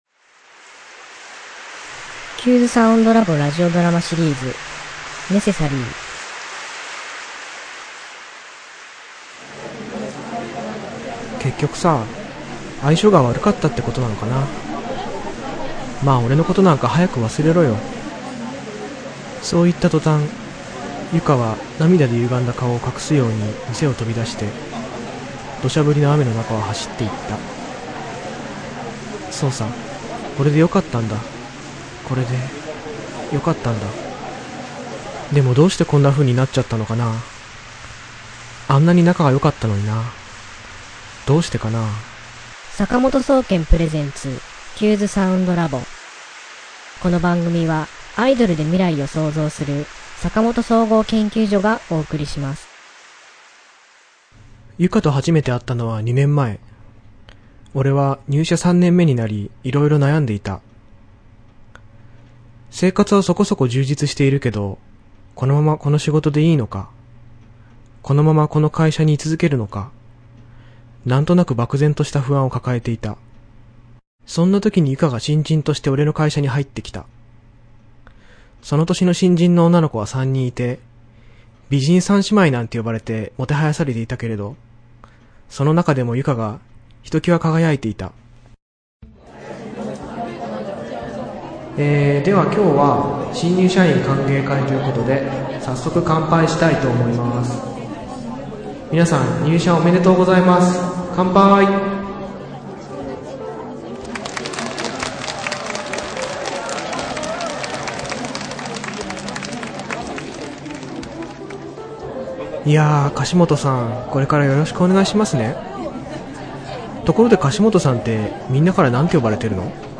今週のテーマ：ラジオドラマ
＜挿入歌＞